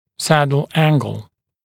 [‘sædl ‘æŋgl][‘сэдл ‘энгл]угол турецкого седла (N-S-Ar)